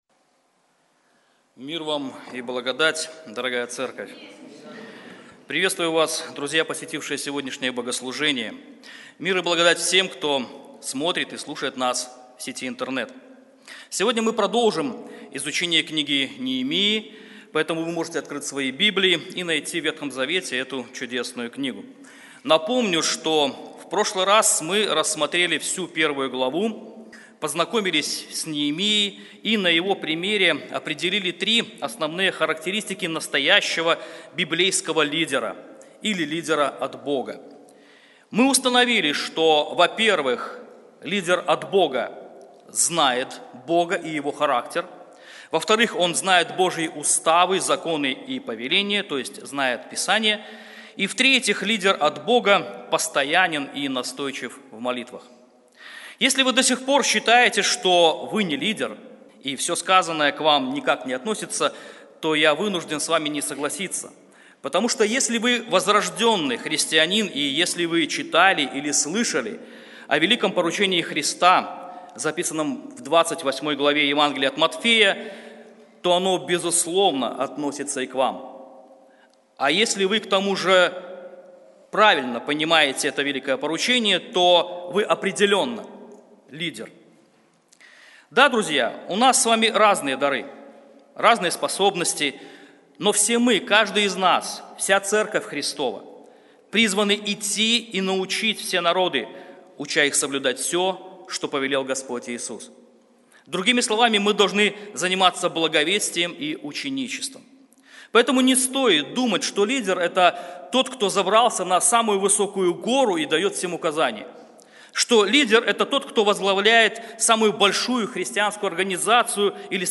Серия проповедей.